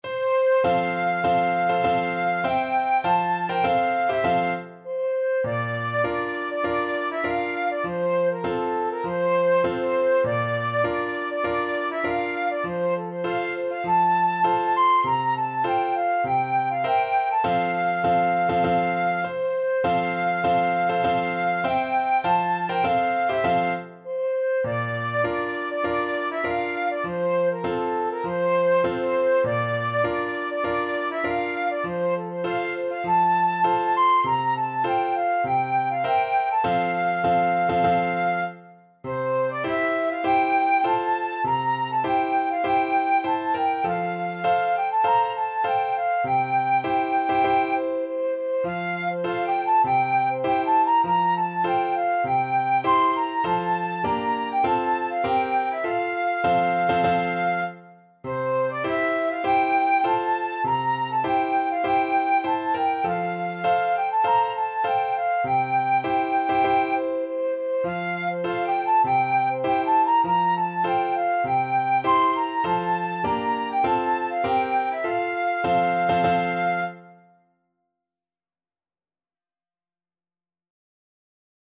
Classical Trad. Hohenfriedberger March Flute version
Flute
Traditional Music of unknown author.
F major (Sounding Pitch) (View more F major Music for Flute )
4/4 (View more 4/4 Music)
A5-C7